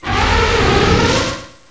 pokeemerald / sound / direct_sound_samples / cries / copperajah.aif